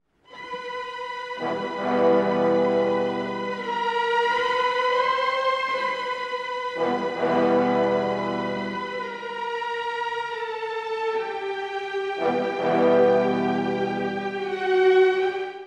裏拍から始まる旋律が推進力を与え、音楽は一気に熱を帯びていきます。
そしてクライマックスを駆け抜けた直後、唐突に2つのピチカートで幕を閉じます。
これは第1楽章と同じ終わり方ですが、今度は急激に静まるような「減衰」のピチカート